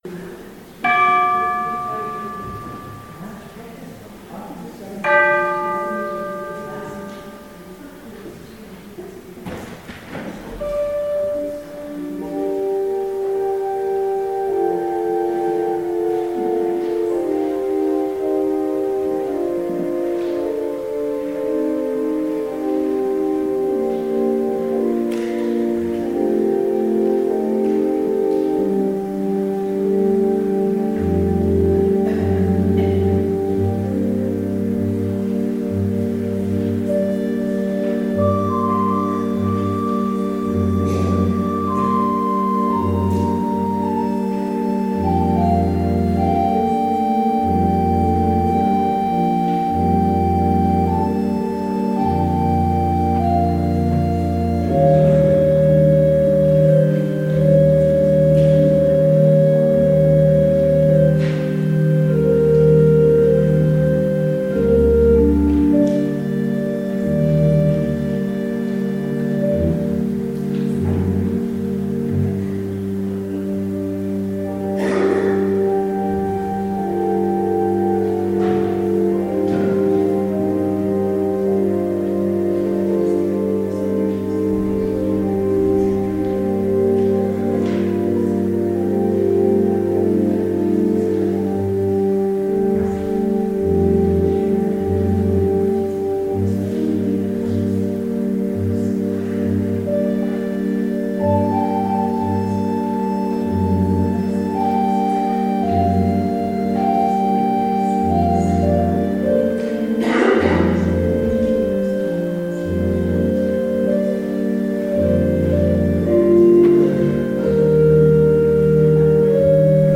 Audio recording of the 10am service
(Note: audio from the eagle microphone is not available, so this recording is of lower than usual quality.)